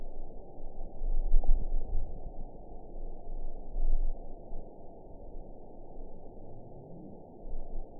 event 910636 date 01/23/22 time 20:40:10 GMT (3 years, 3 months ago) score 8.28 location TSS-AB08 detected by nrw target species NRW annotations +NRW Spectrogram: Frequency (kHz) vs. Time (s) audio not available .wav